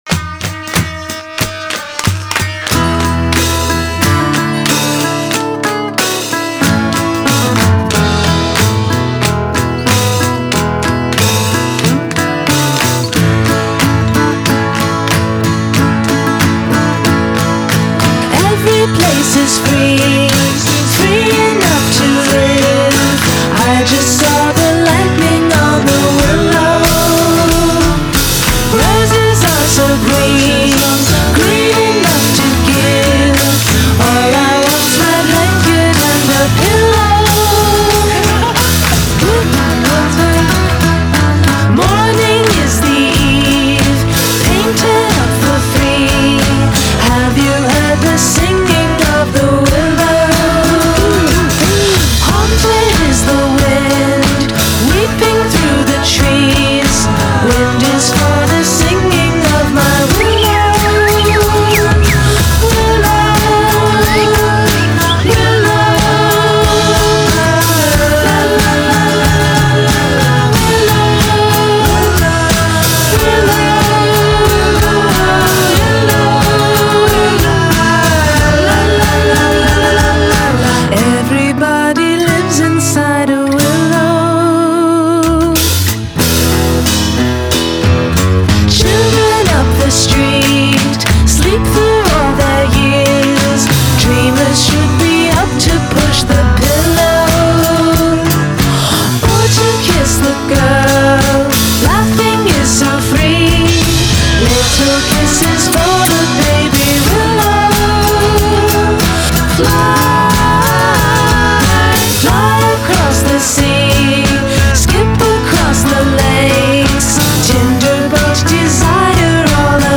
Folk-Rock duo
vocals/guitar
guitar, bass, vocals and keys